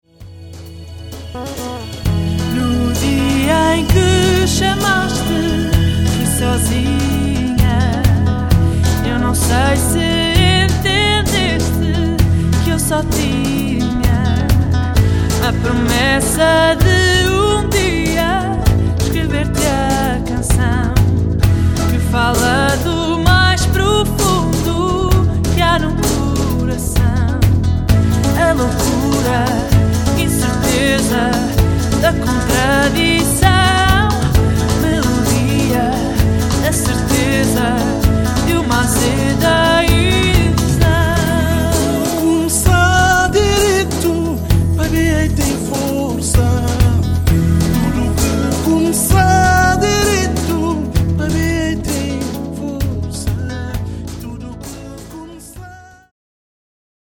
Giving central stage to Portuguese folk
cello, guitar
mobius megatar
drums